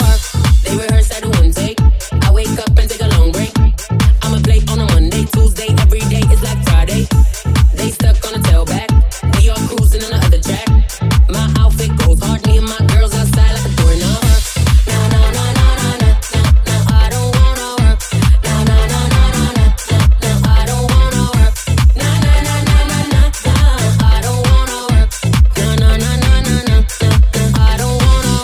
Genere: pop,dance,deep,disco,house.groove,latin,hit